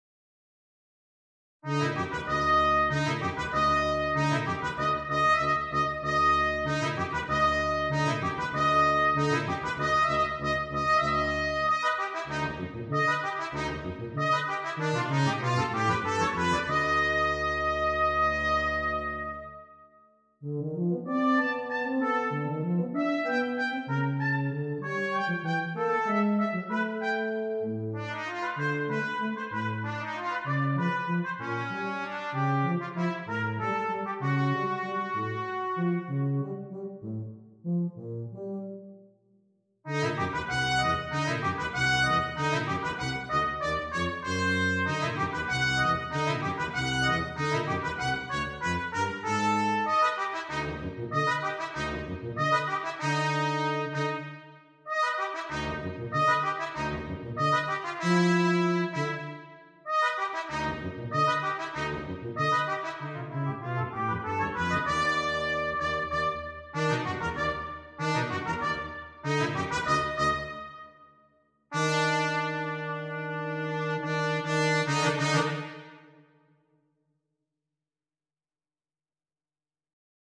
Trumpet, Tuba